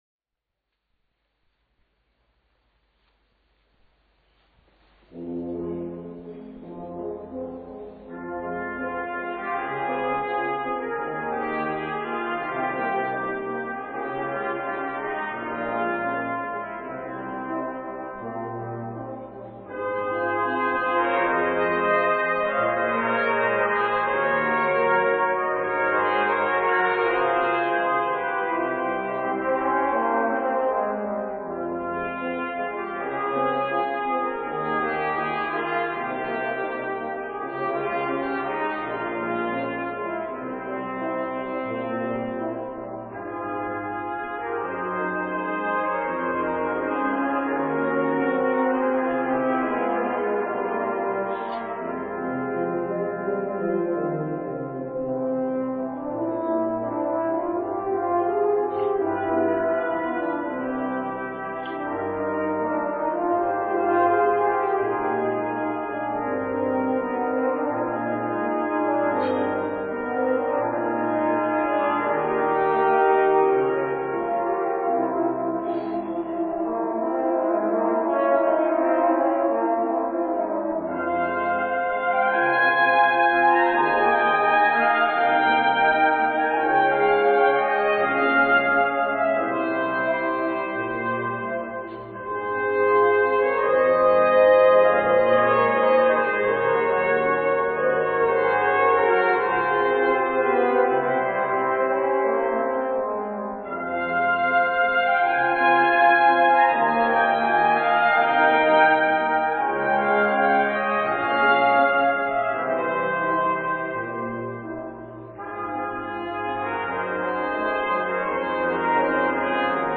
04 orchestres cuivres.mp3